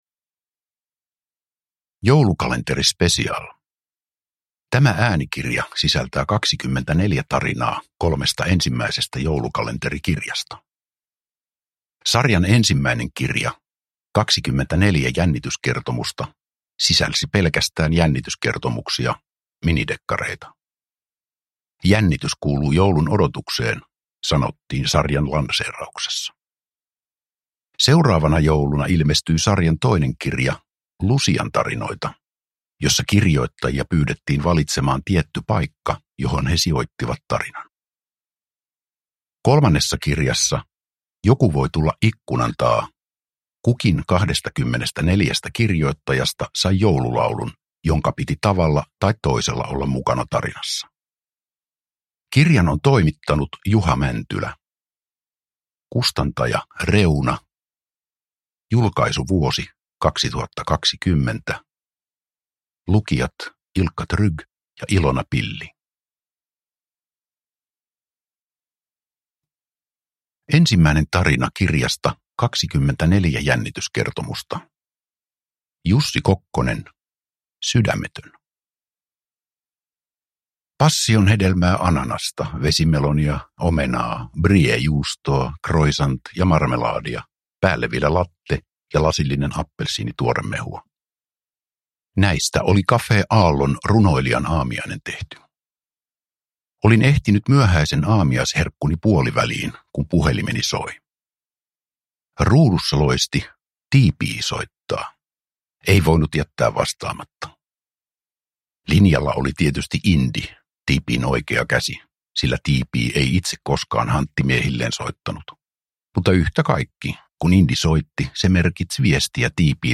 Joulukalenteri Special – Ljudbok – Laddas ner
iloinen nuori naisääni
tunnelmallinen vanhan herran ääni